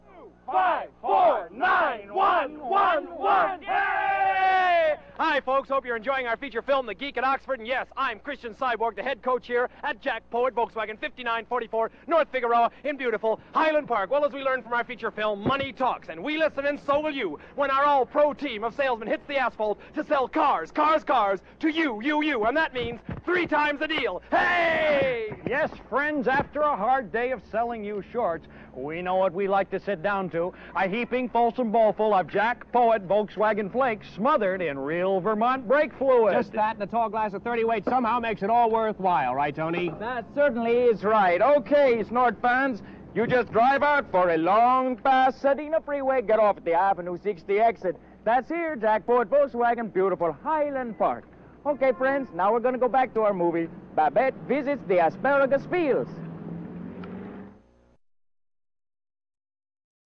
Jack Poet TV commercial